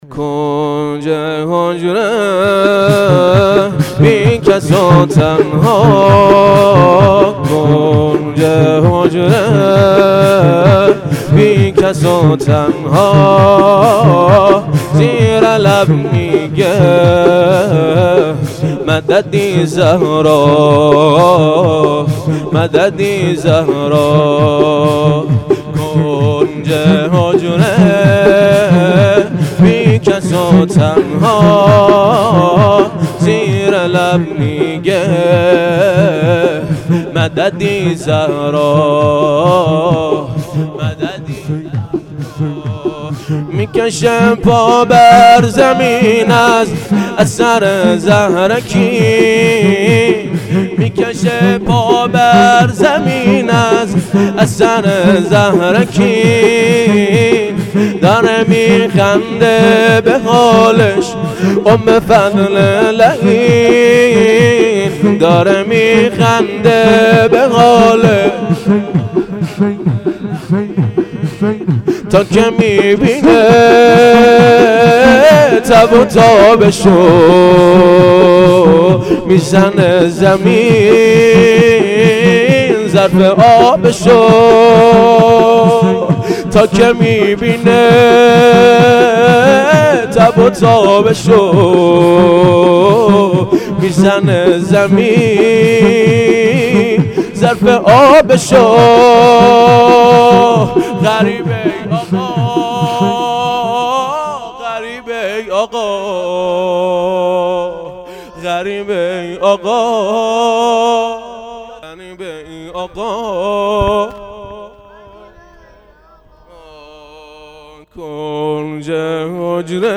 شور| کنج حجره بی کس و تنها